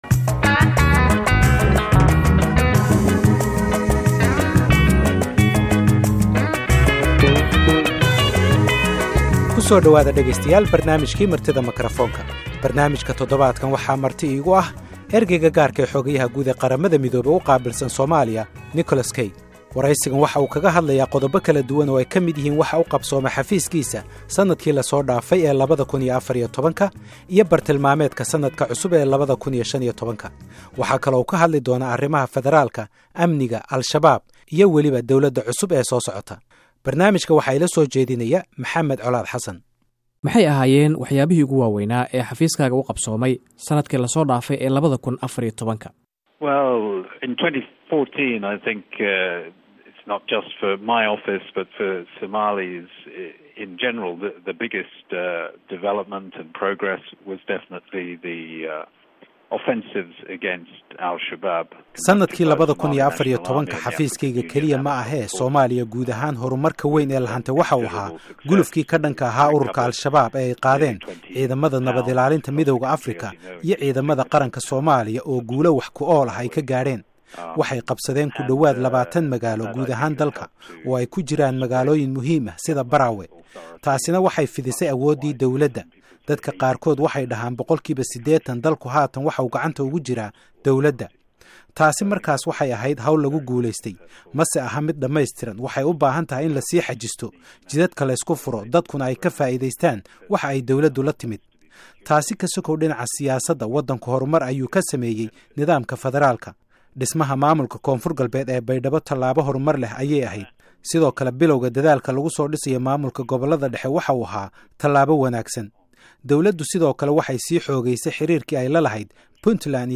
Ergeyga Gaar ah ee Qaramada Midoobay ee Somalia Nicholas Kay ayaa lagu waraystay Barnaamijka Martida makaroofanka ee todobaadkan o oka baxa idaacada VOA somali. Wareysigan ayuu Mr. Kaga hadlayaa qodobo kala duwan, gaar ahaan waxa u qabsoomay xafiiskiisa sanadkii la soo dhaafay, arimaha amaanka ee Somalia iyo waxa u qorshaysan sanadkan 2015.